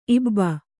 ♪ ibba